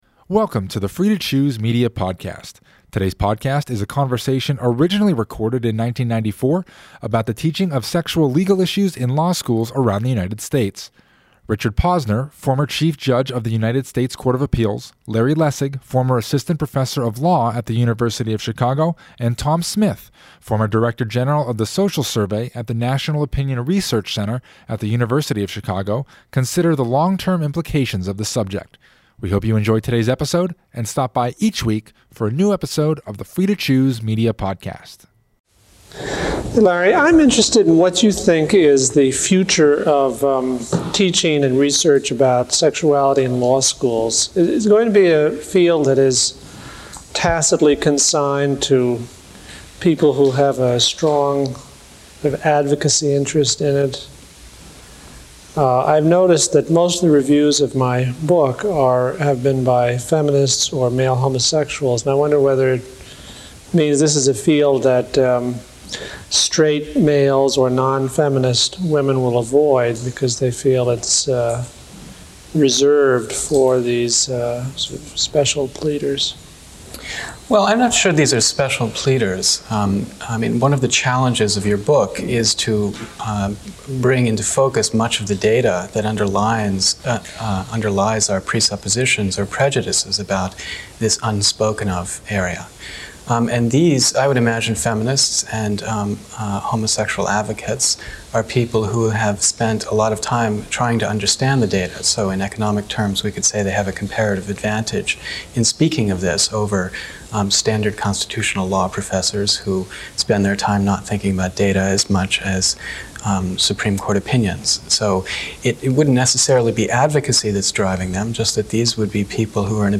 This conversation, originally recorded in 1994, looks at the teaching of sexual legal issues in law schools around the United States. The discussion considers the long-term implications for our society based on the lack of data on sexual behavior around the country as the participants try to discern conclusions that can be taken from this study of sexual behavior.